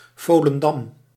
Volendam (Dutch pronunciation: [ˌvoːlə(n)ˈdɑm]
Nl-Volendam.ogg.mp3